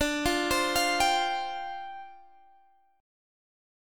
Listen to Dm11 strummed